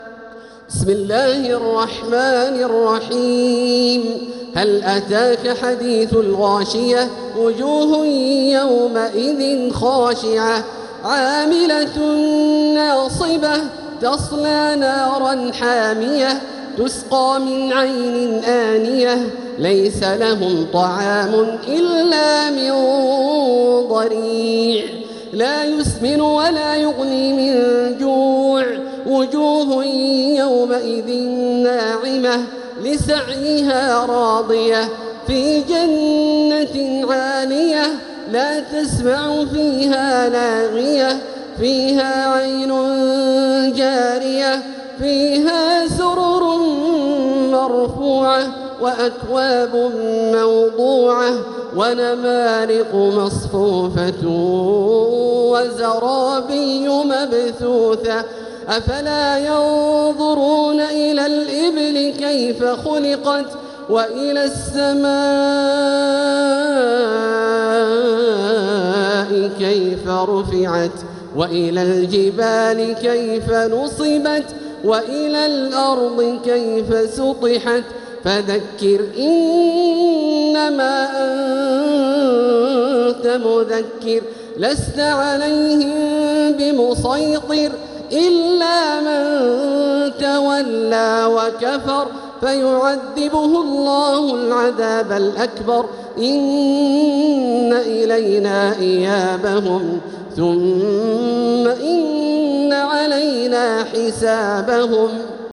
سورة الغاشية | مصحف تراويح الحرم المكي عام 1446هـ > مصحف تراويح الحرم المكي عام 1446هـ > المصحف - تلاوات الحرمين